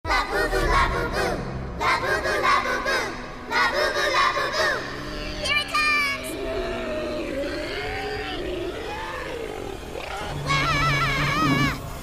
South Park Demon Labubu Summoning Ritual sound effect
South-Park-is-making-fun-of-Labubus-by-a-doing-demonic-ritual-sound-effect.mp3